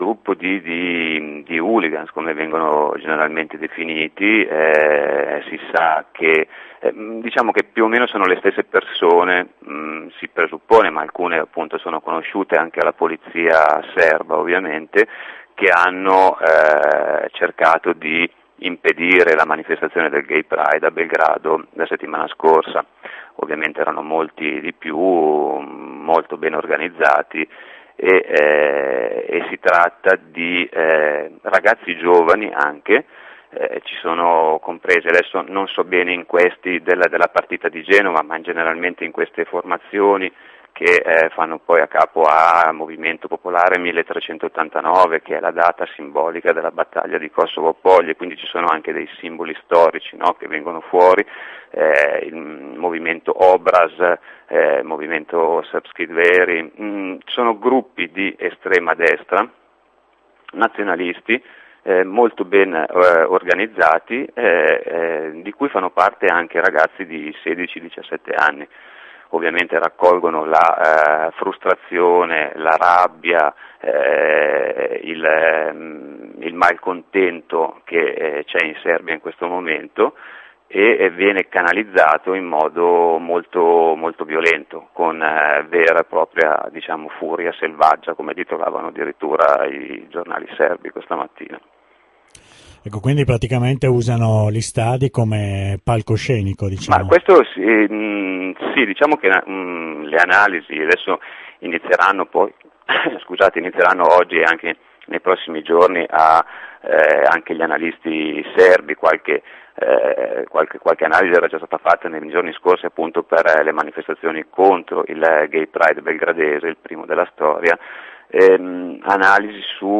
su Radio Onda D'Urto